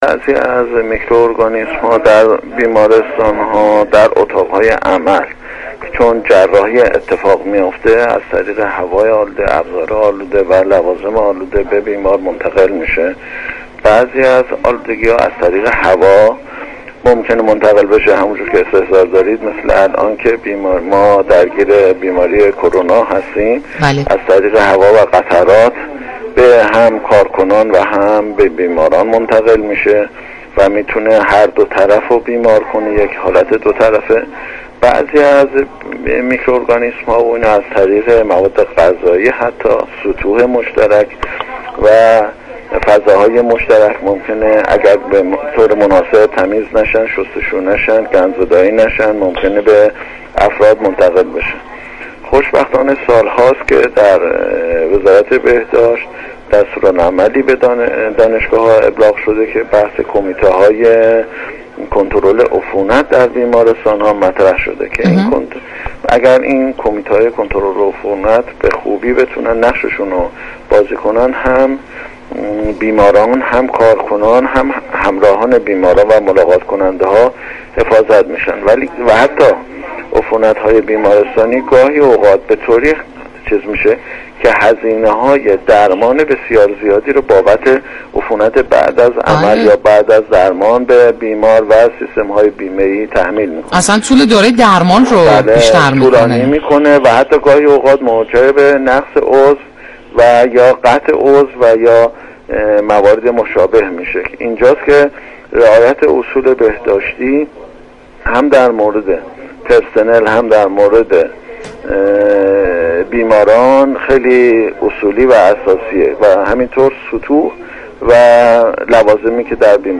از همین رو دال رادیوجوان در گفتگو با دو پزشك متخصص بیماری‌های عفونی به بررسی این موضوع پرداخته است.